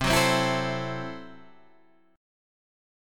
C 6th Flat 5th